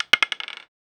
falling.wav